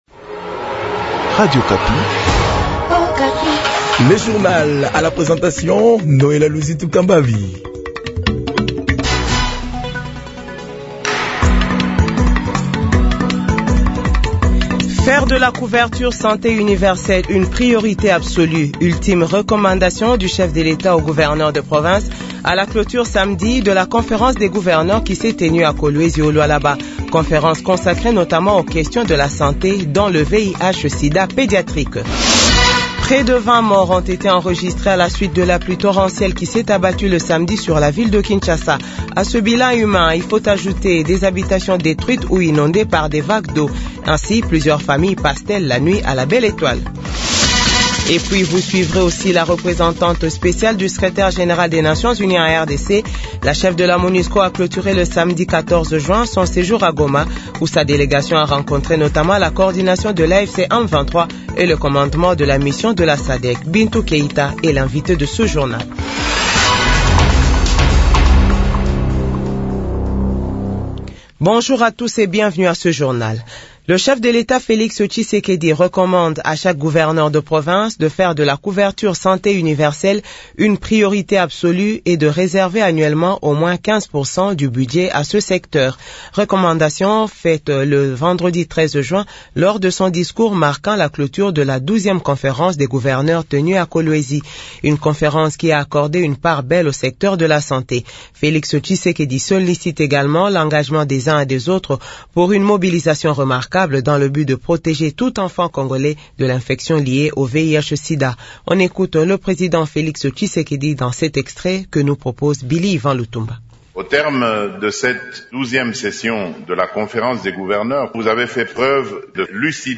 Journal 6h-7h